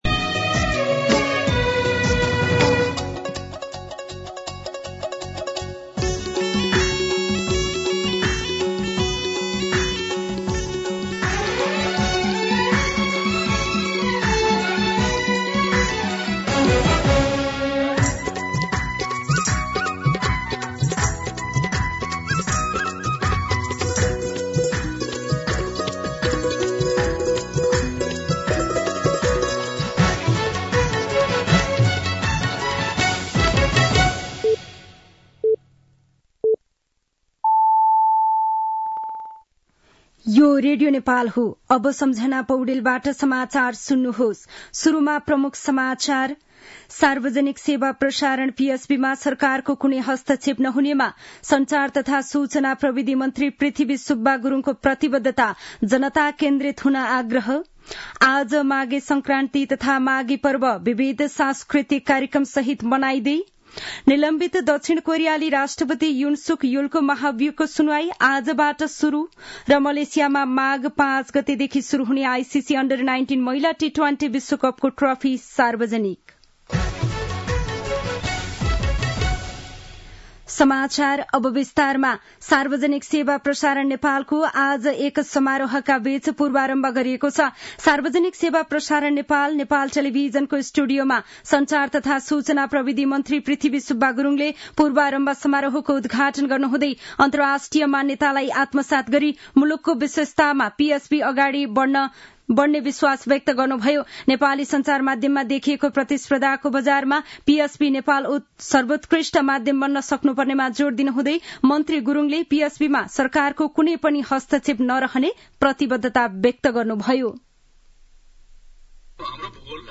दिउँसो ३ बजेको नेपाली समाचार : २ माघ , २०८१
3-pm-News.mp3